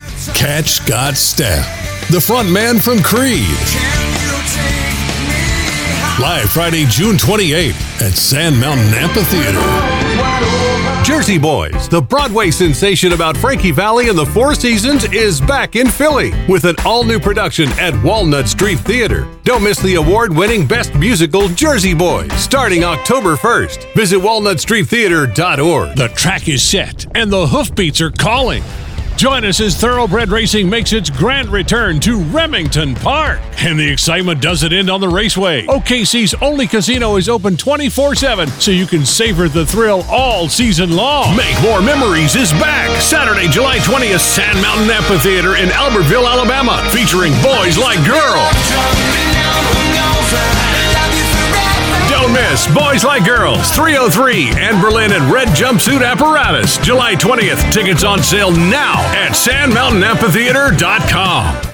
Concert Spots